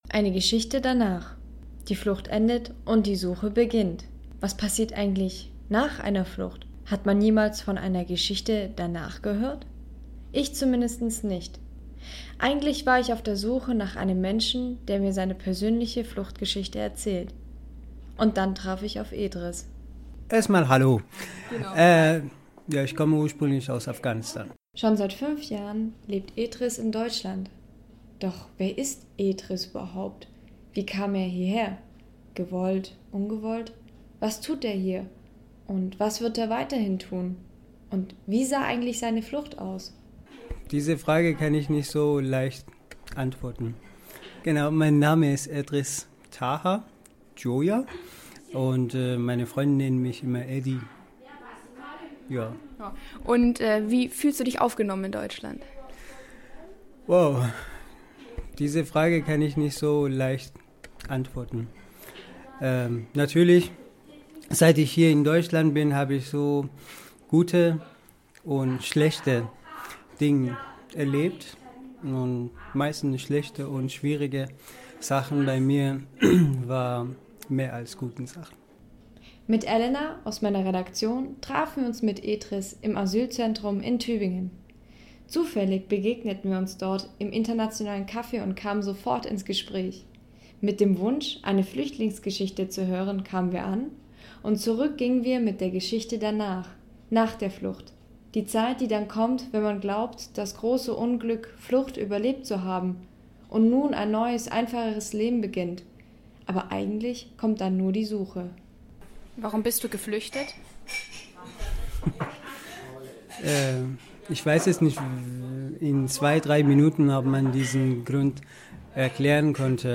Zufällig begegneten wir uns im Internationalen Café und kamen ins Gespräch.